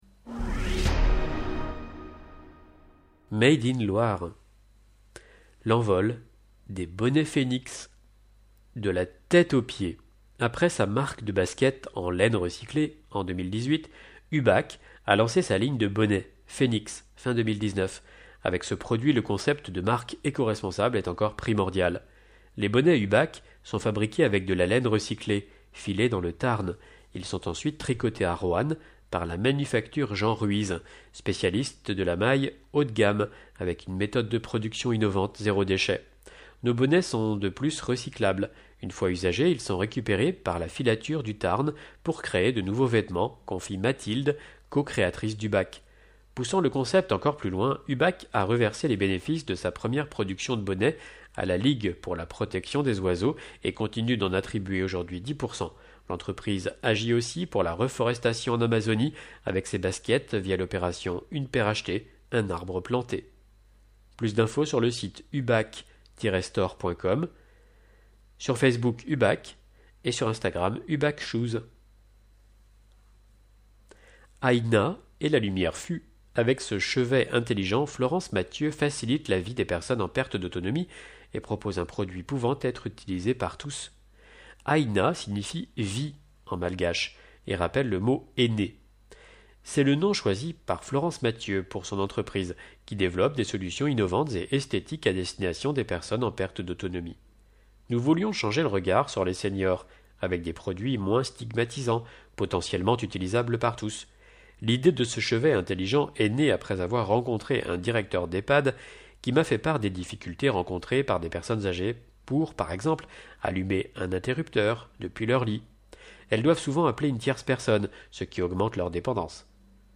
Loire Magazine n°139 version sonore